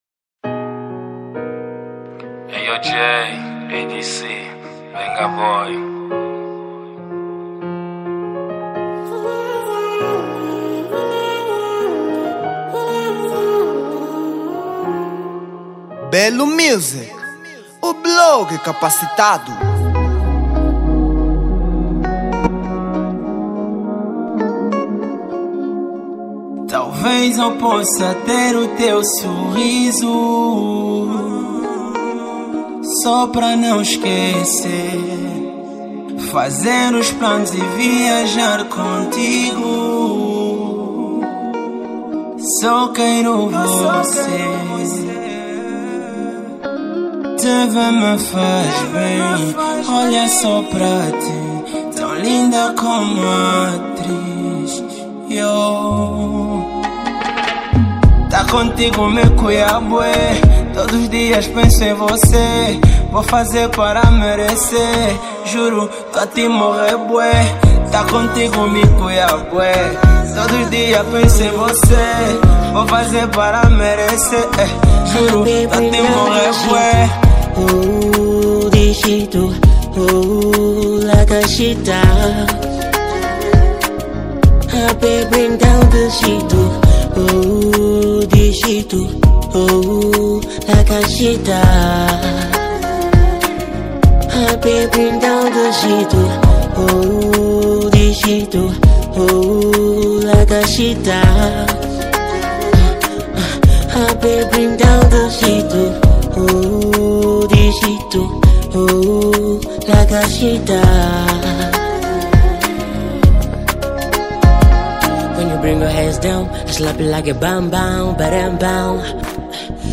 Género: Afro Beats